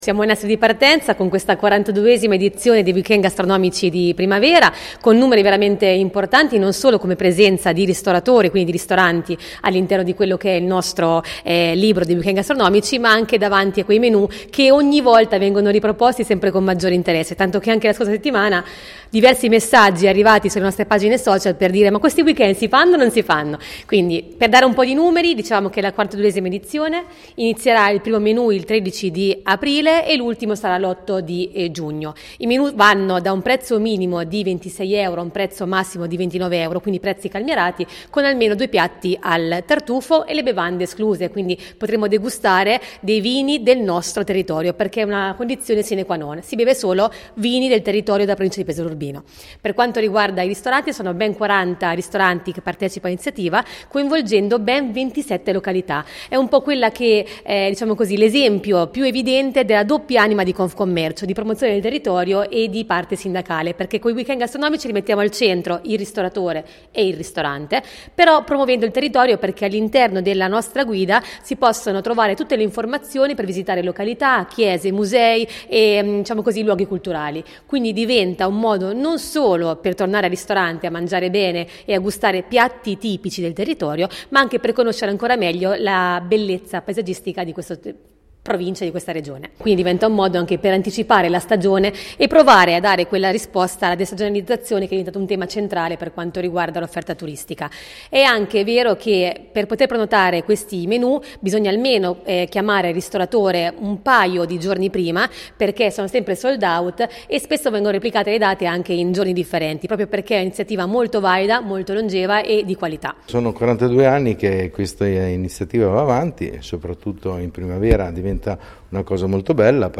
Dal 13 aprile all’8 giugno, in 40 ristoranti di 27 località della provincia di Pesaro Urbino e del Montefeltro. Ecco le nostre interviste, con i vertici di Confcommercio Marche Nord.